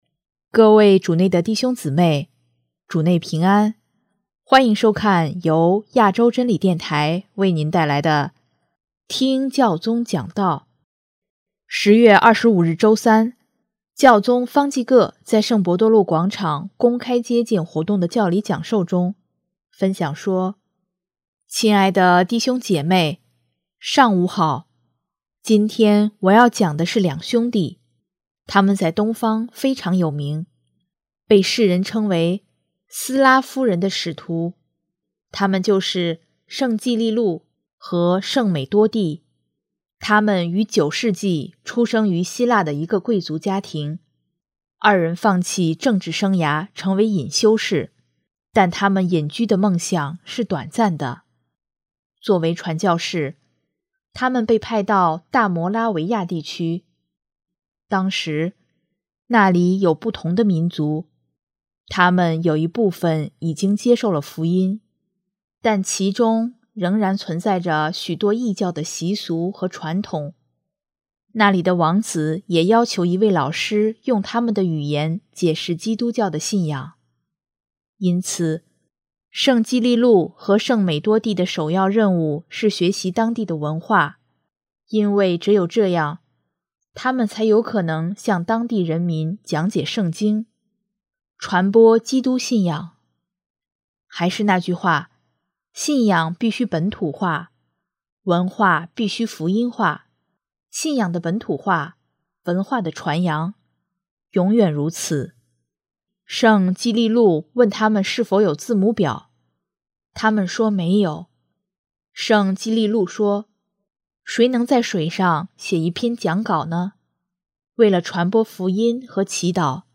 10月25日周三，教宗方济各在圣伯多禄广场公开接见活动的教理讲授中，分享说：